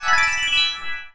heal_loop_01.wav